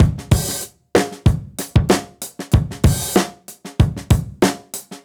Index of /musicradar/dusty-funk-samples/Beats/95bpm